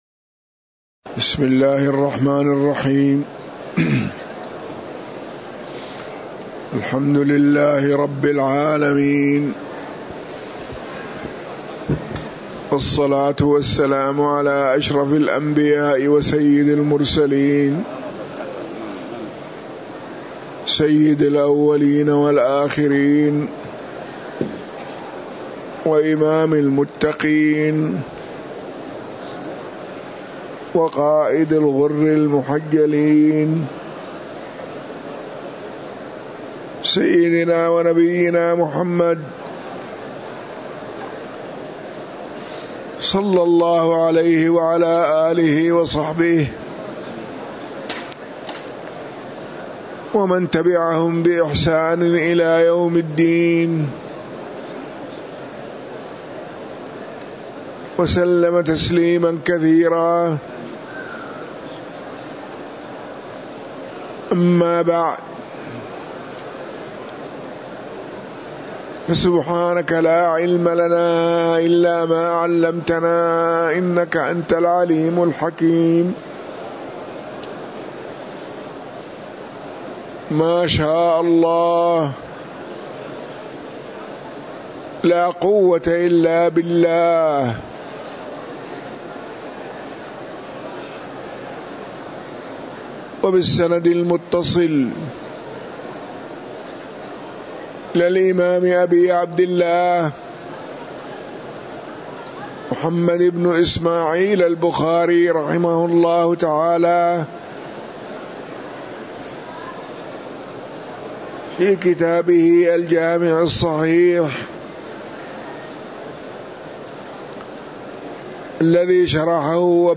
تاريخ النشر ١٣ صفر ١٤٤٥ هـ المكان: المسجد النبوي الشيخ